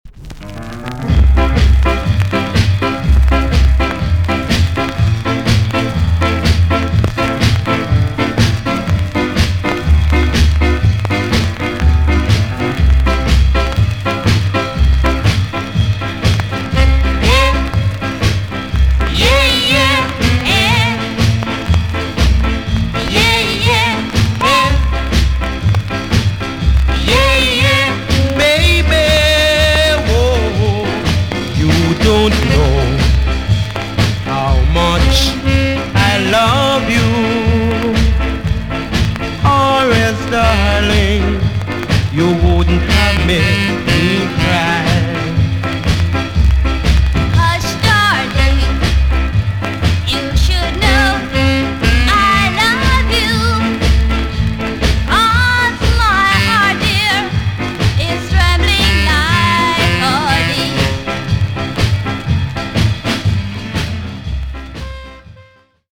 TOP >SKA & ROCKSTEADY
VG+~VG ok 軽いチリノイズが入ります。